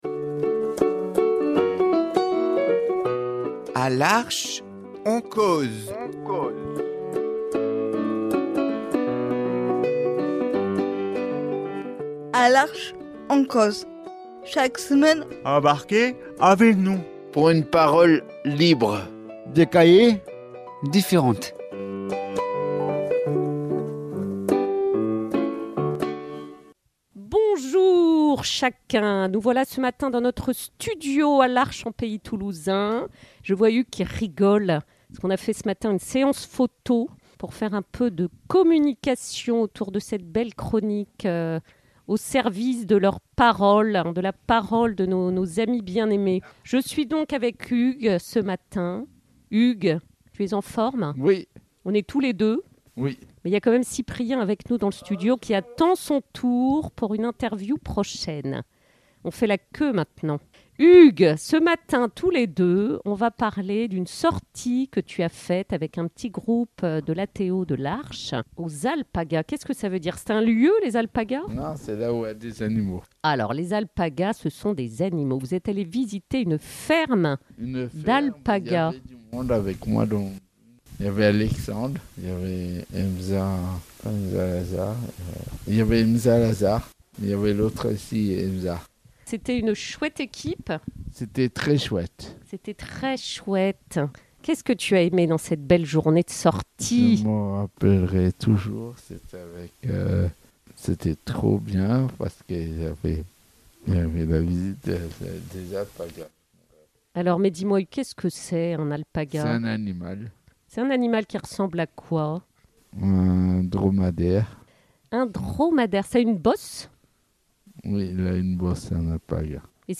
Il raconte au micro cette chouette découverte !